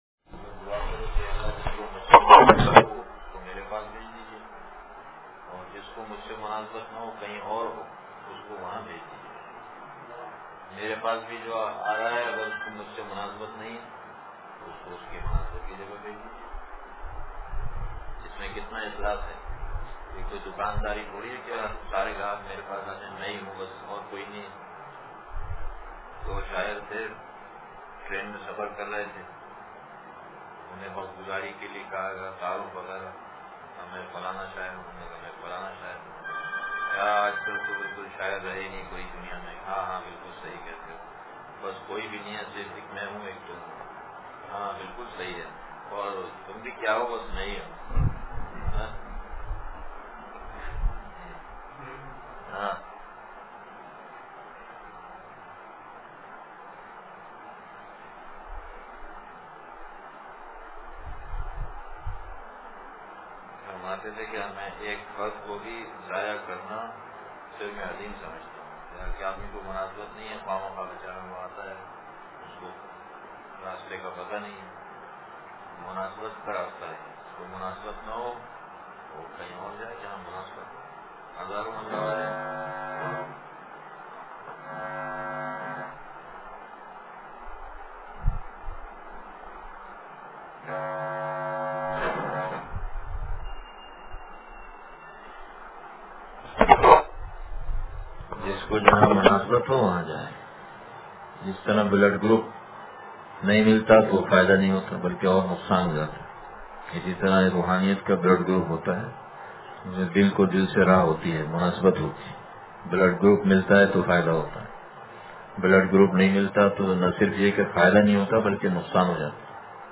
پھر گنبدِ خضراء سے منّور ہوئے ہیں ہم – مجلس بروز جمعرات – دنیا کی حقیقت – نشر الطیب فی ذکر النبی الحبیب صلی اللہ علیہ وسلم